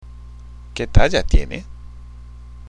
＜発音と日本語＞
（ケ　タジャ　ティエネ？）